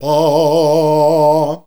AAAAAH  A#.wav